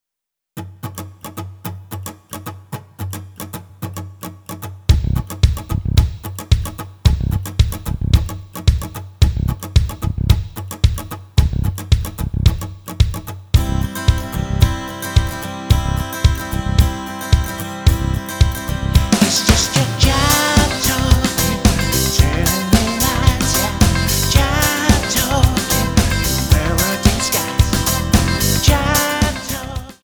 Tonart:C Multifile (kein Sofortdownload.
Die besten Playbacks Instrumentals und Karaoke Versionen .